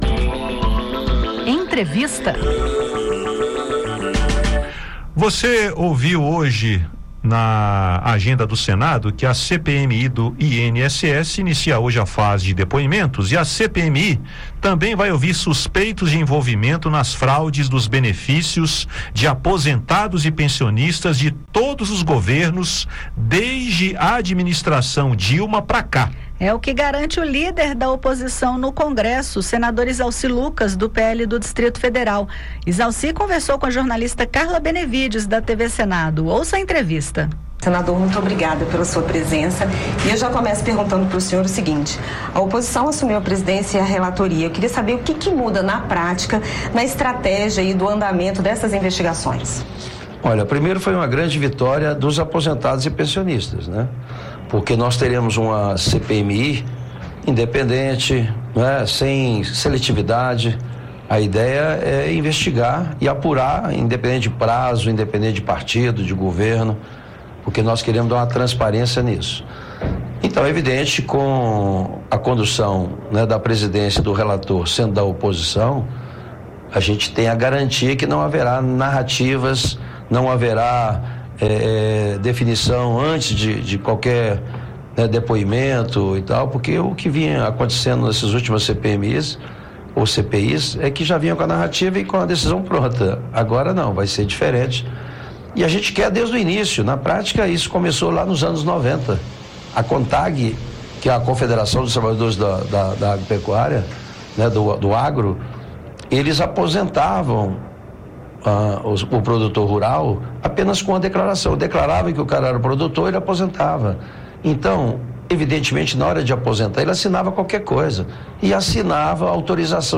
A Comissão Parlamentar Mista de Inquérito do INSS vai apurar suspeitas de fraudes em aposentadorias e pensões. Em entrevista